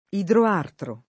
idroartro [ idro # rtro ] → idrartro